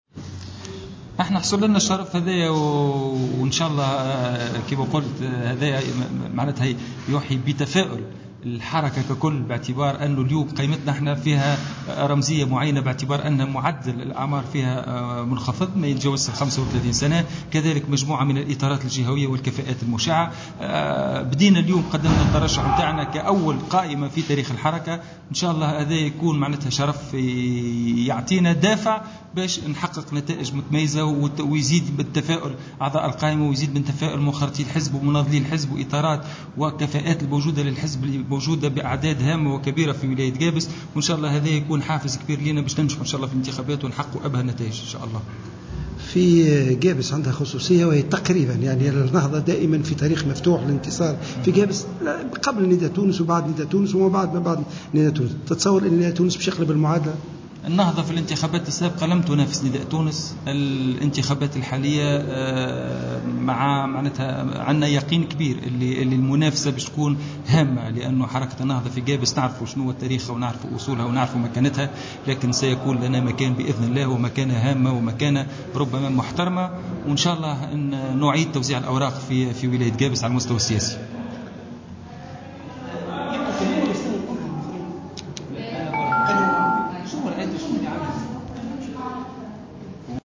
Le président de la liste de Nidaa Tounes à Gabès, Hassouna Nasfi, a assuré, dans une déclaration accordée à Jawhara Fm, que Nidaa Tounes est capable de rivaliser avec le mouvement Ennahdha lors des prochaines élections à Gabès malgré sa côte dans la région.